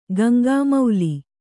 ♪ Gaŋgāmauli